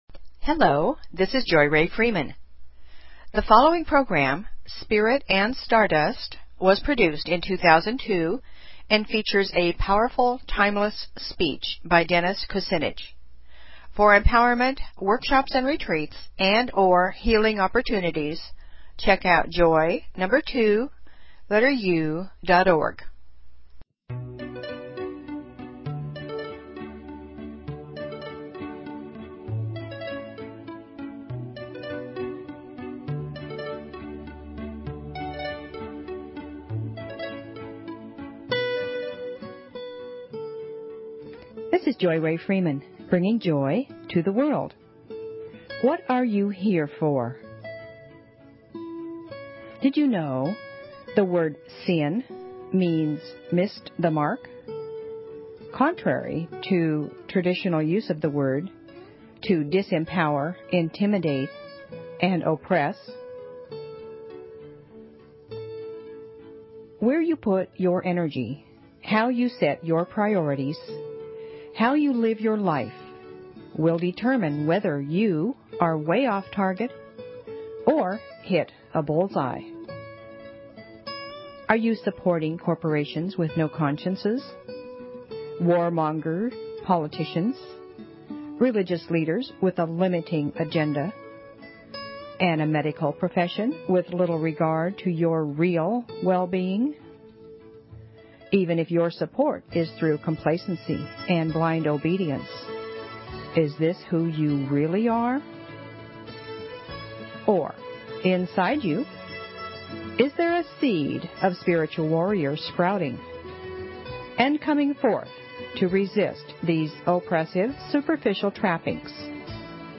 Talk Show Episode, Audio Podcast, Joy_To_The_World and Courtesy of BBS Radio on , show guests , about , categorized as
Music, poetry, affirmations, stories, inspiration . . .